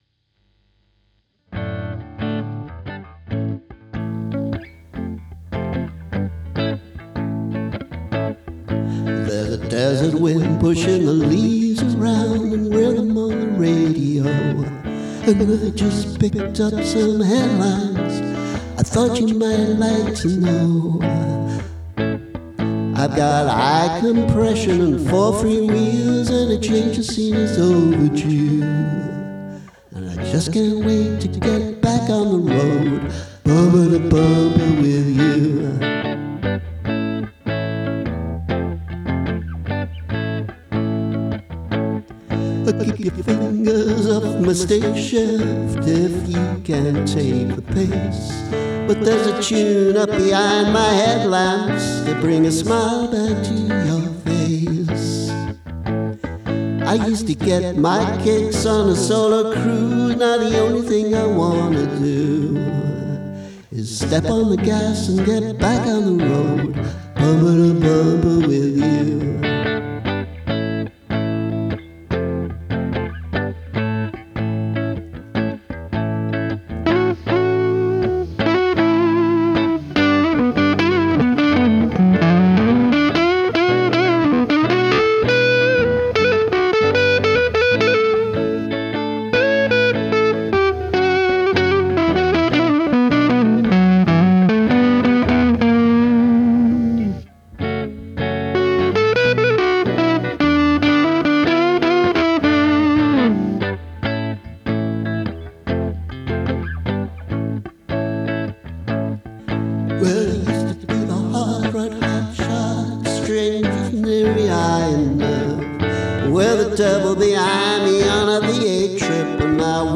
Thought I’d try this with a more-than-usually-period vocal feel to the demo, but perhaps 60s rock ‘n’ roll isn’t really me: